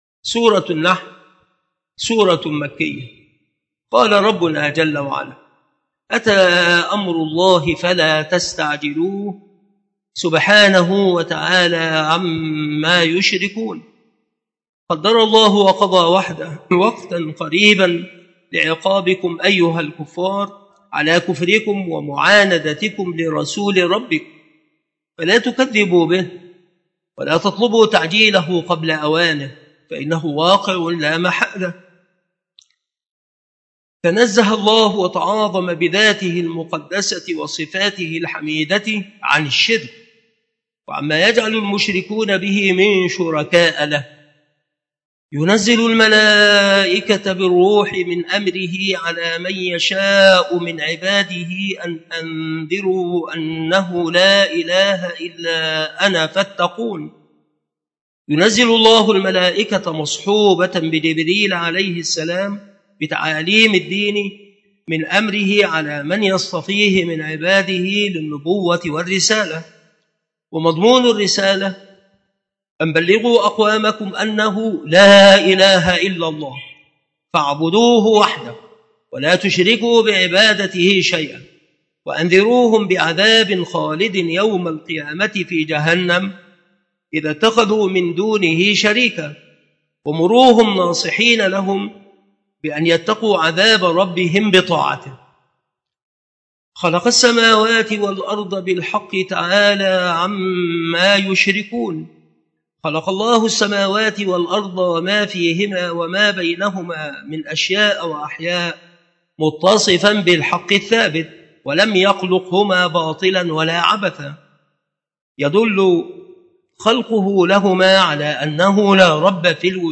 التصنيف التفسير
مكان إلقاء هذه المحاضرة بالمسجد الشرقي بسبك الأحد - أشمون - محافظة المنوفية - مصر